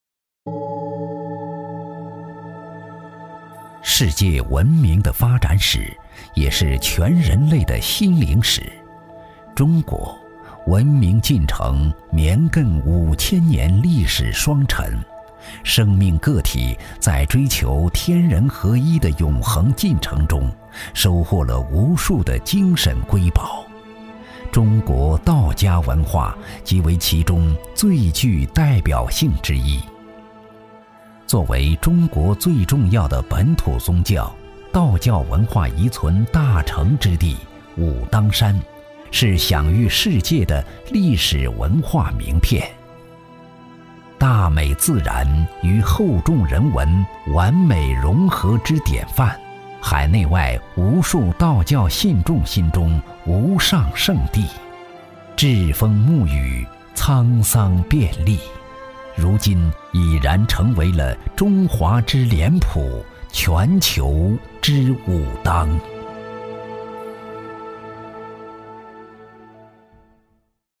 男国195_纪录片_人文历史_问道武当.mp3